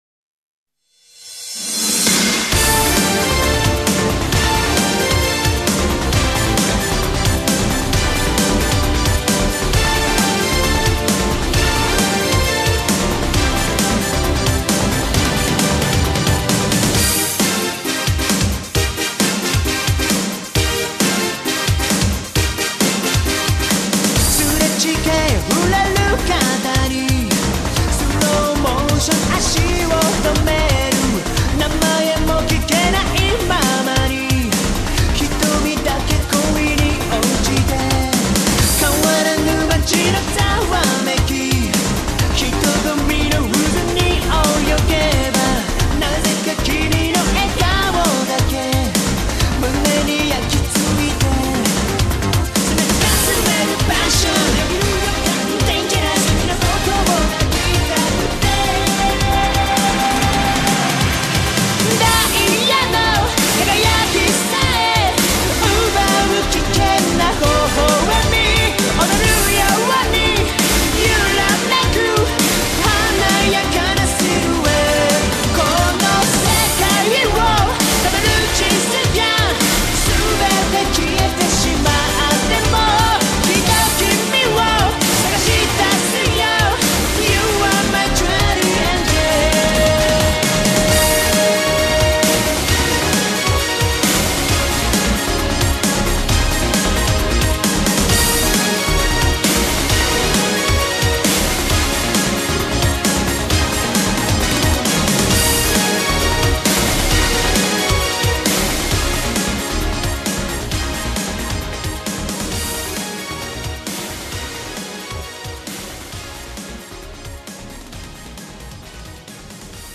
BPM133-133
Audio QualityPerfect (High Quality)
J-Pop duo!